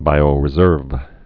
(bīō-rĭ-zûrv)